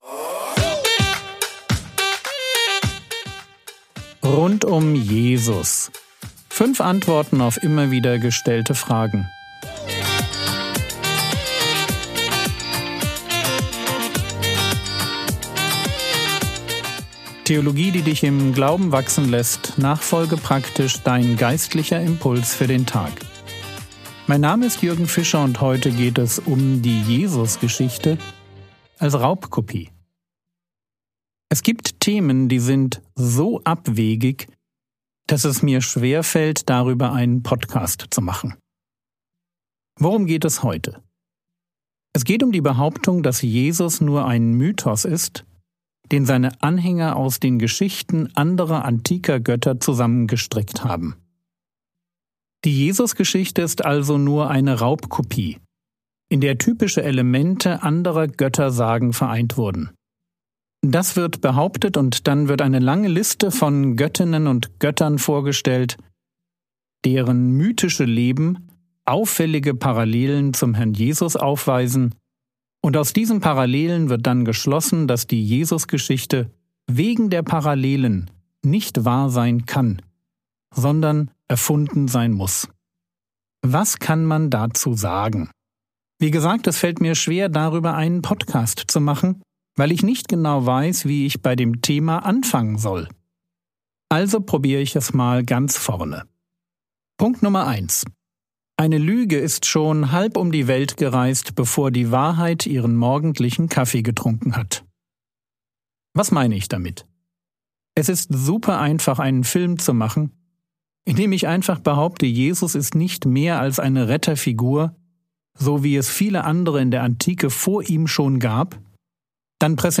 Rund um Jesus (3/5) ~ Frogwords Mini-Predigt Podcast